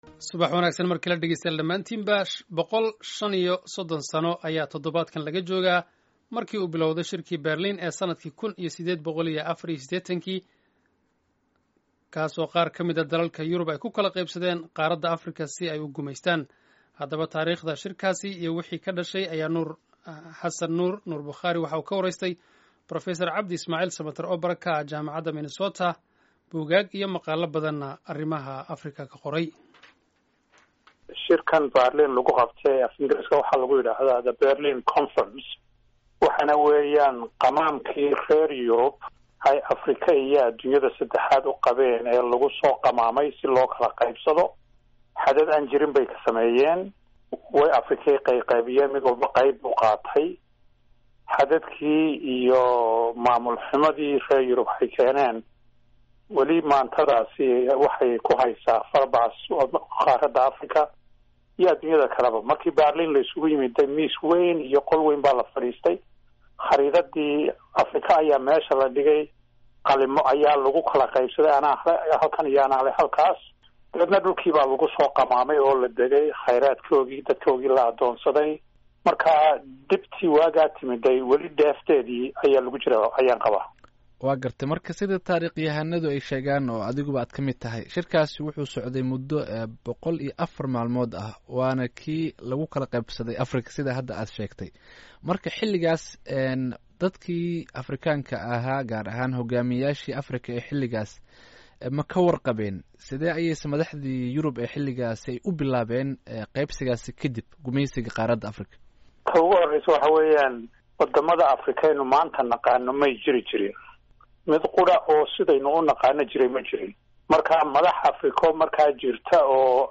wareysiga